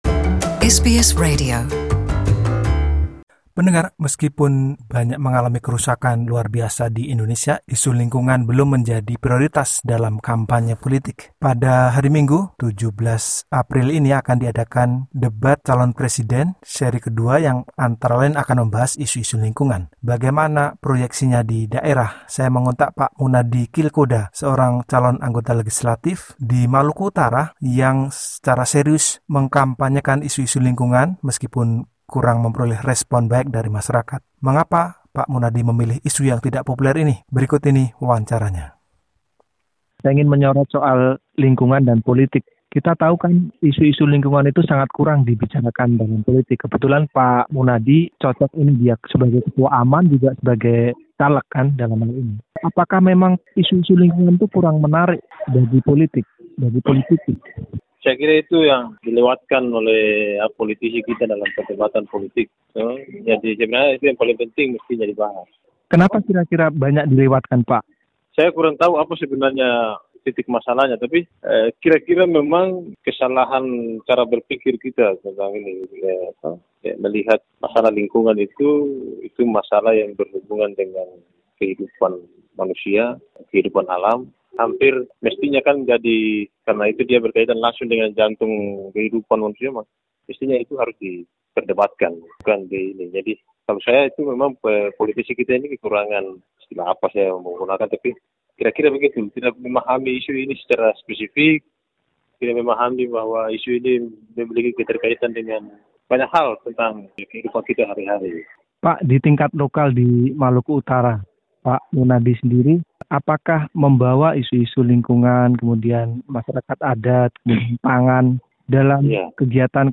Berikut wawancara selengkapnya.